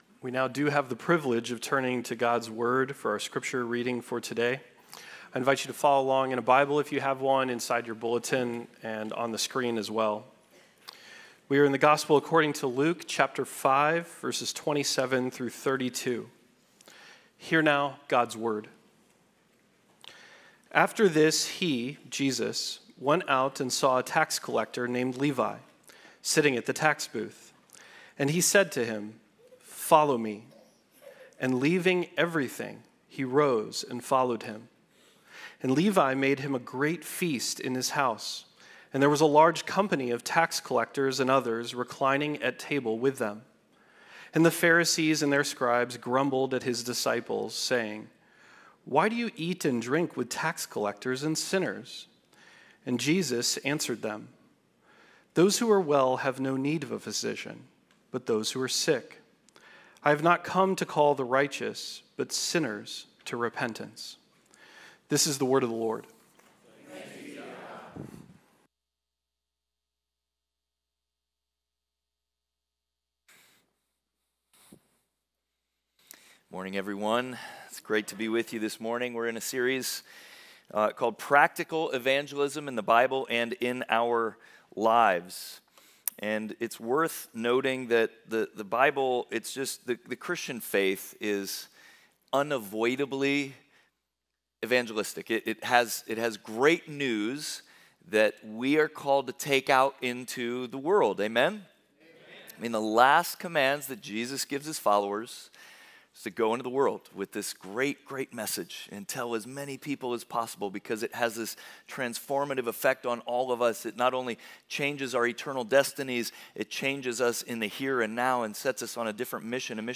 Service Type: Sunday Worship
9.25-sermon-audio.m4a